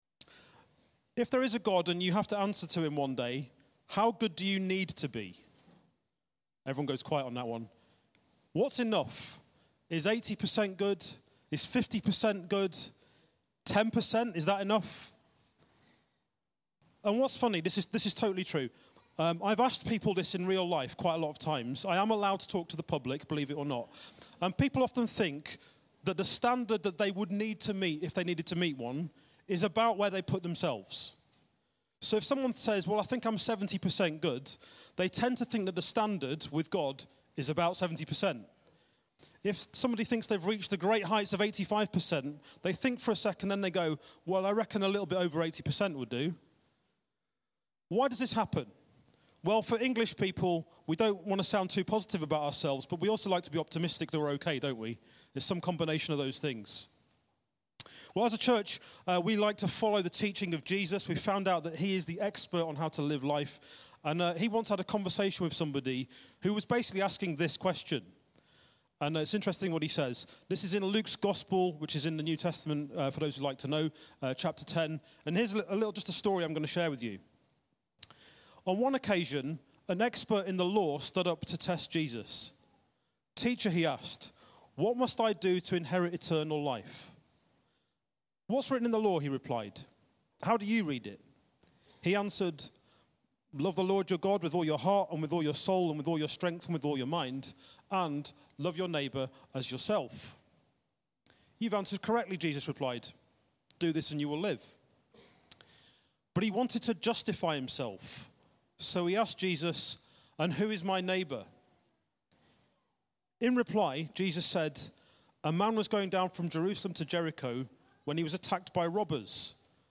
Baptisms 9th June 2024